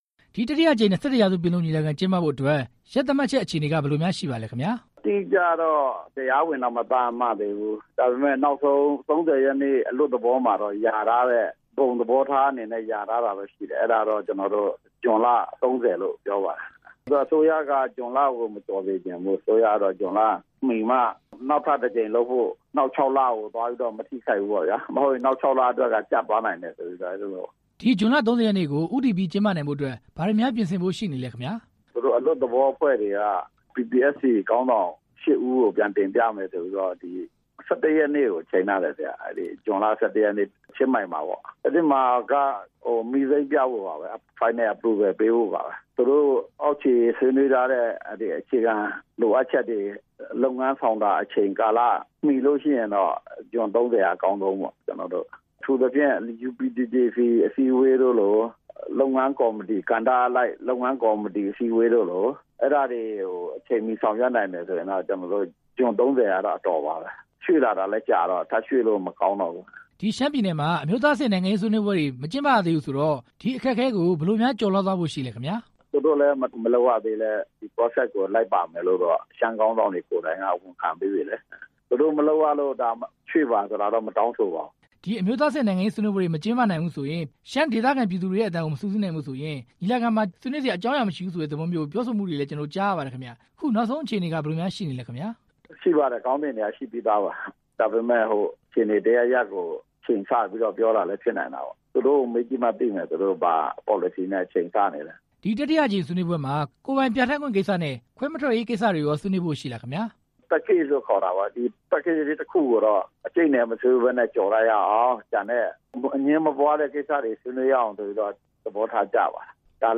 ဇွန်လ ၃၀ ရက်နေ့မှာကျင်းပမယ့် ၂၁ ပင်လုံအကြောင်း မေးမြန်းချက်